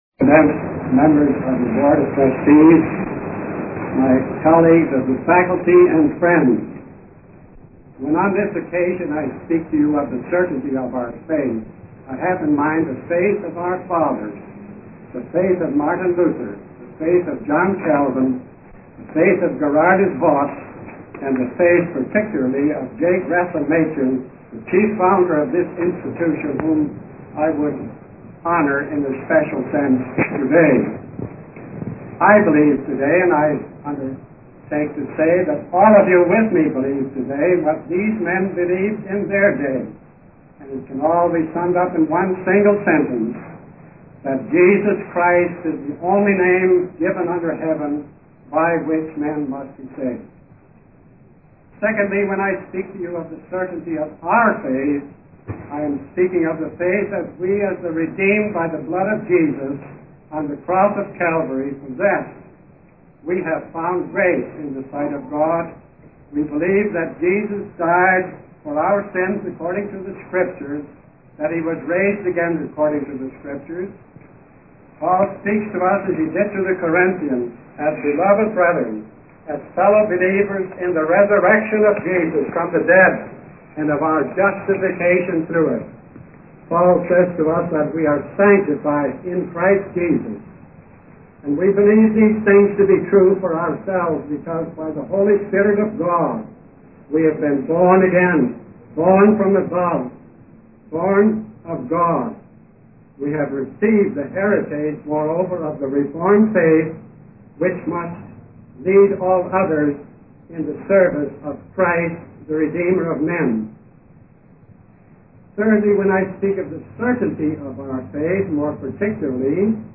In this sermon, the preacher emphasizes the contrast between the wisdom of the world and the wisdom of God.